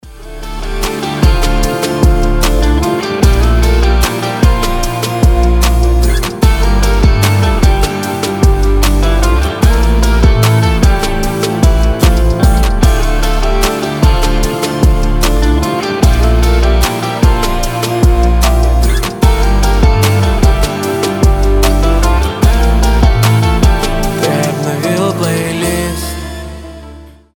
• Качество: 320, Stereo
гитара
грустные
красивая мелодия
Грустная инструменталочка